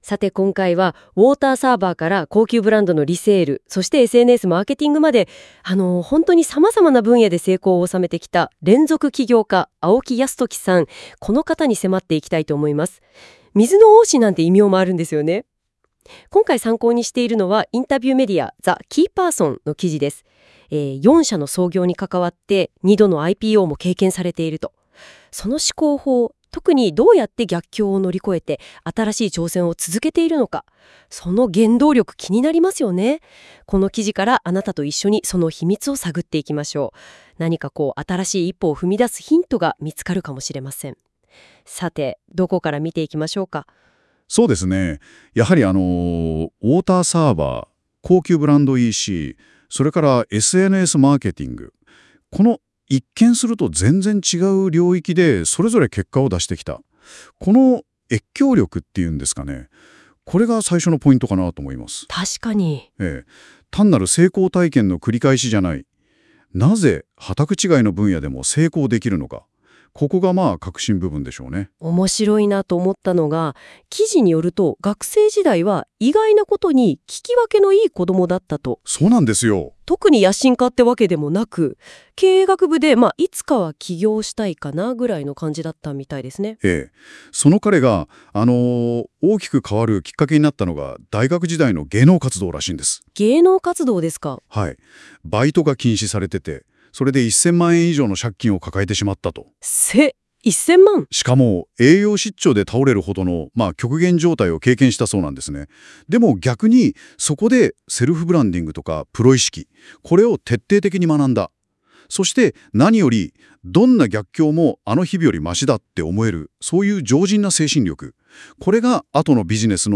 Interviewee